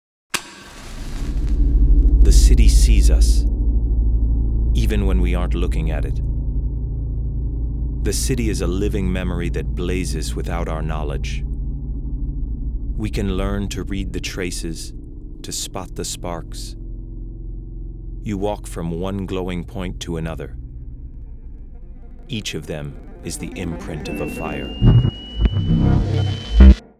DOCUMENTARY . MEMORIES . FIRES
A digital audio story, the work mixes fiction and musical composition, but is also enriched by the participation of the inhabitants who transmit life stories linked to the places, in order to anchor the project in the territory.